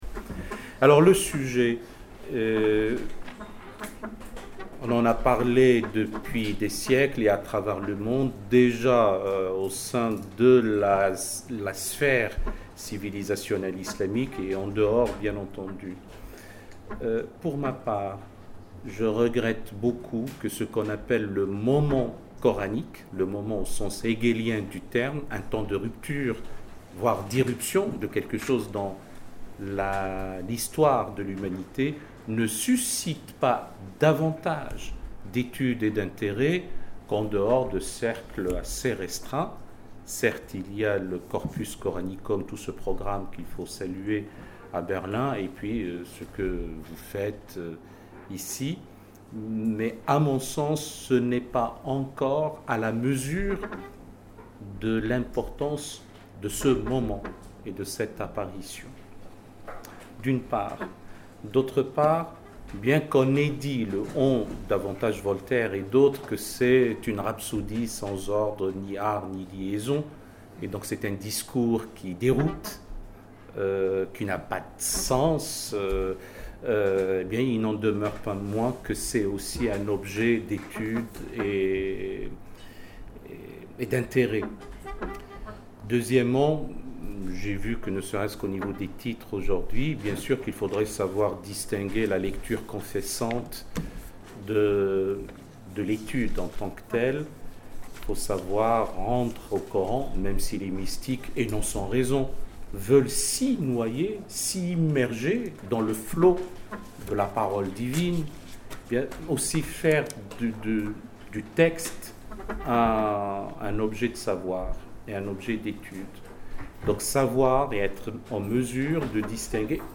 Ghaleb Bencheikh (invité d’honneur), théologien, philosophe, président de la Conférence mondiale des religions pour la paix. Accédez à l'intégralité de la conférence en podcast audio dans l'onglet téléchargement